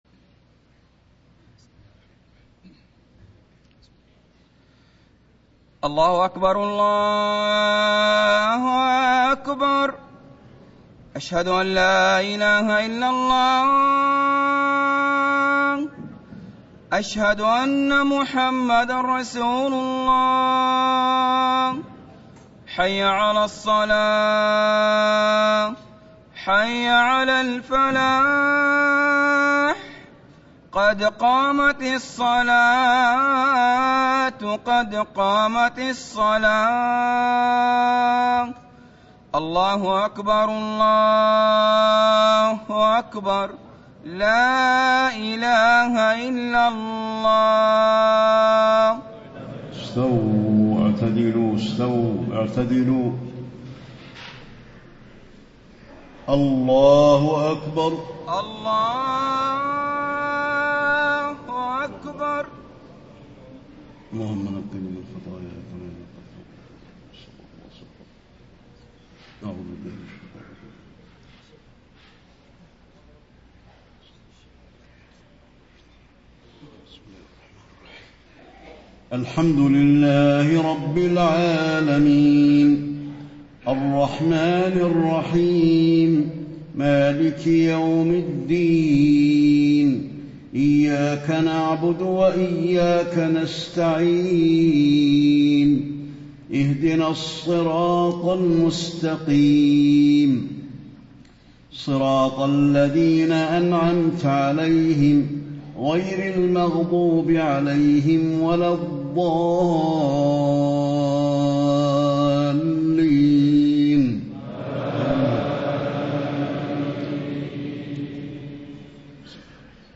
صلاة الجمعة 7-4-1435هـ سورتي الزلزلة و الإخلاص > 1435 🕌 > الفروض - تلاوات الحرمين